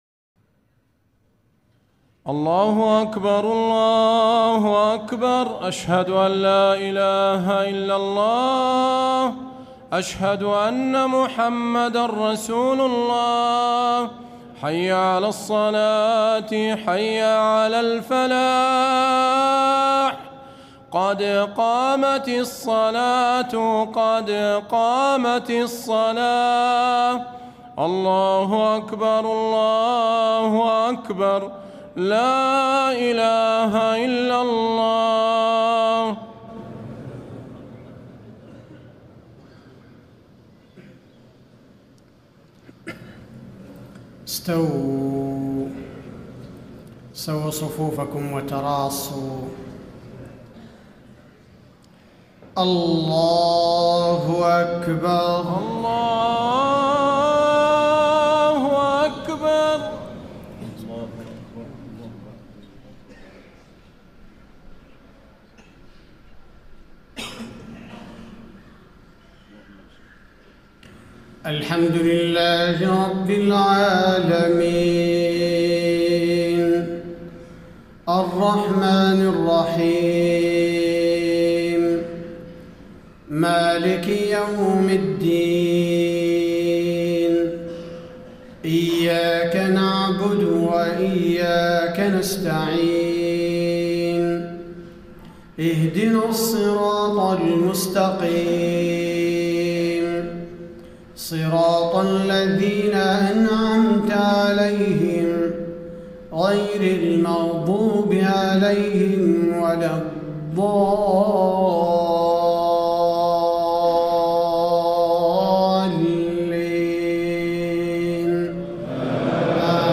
صلاة العشاء 1-6-1435 ما تيسر من سورة الكهف > 1435 🕌 > الفروض - تلاوات الحرمين